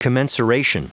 Prononciation du mot commensuration en anglais (fichier audio)
Prononciation du mot : commensuration